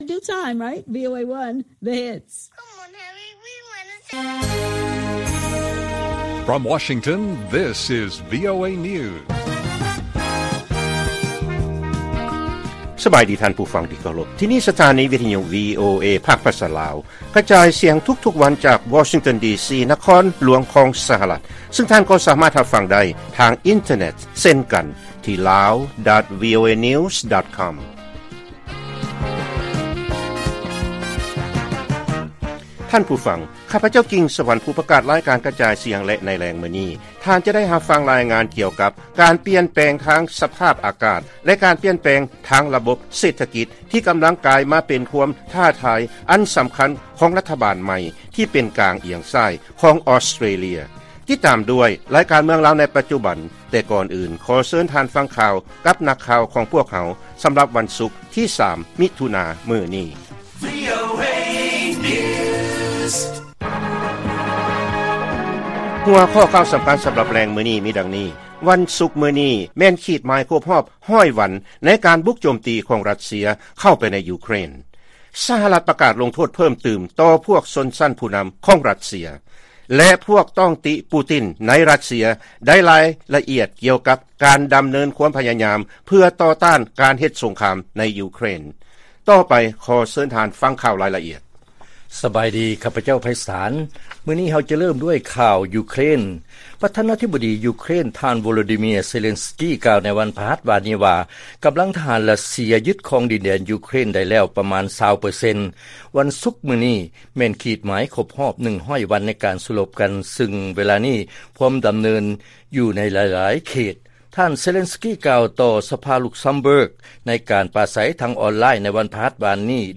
ລາຍການກະຈາຍສຽງຂອງວີໂອເອ ລາວ: ວັນສຸກມື້ນີ້ ແມ່ນຂີດໝາຍຄົບຮອບ 100 ວັນຂອງການສູ້ລົບໃນ ຢູເຄຣນ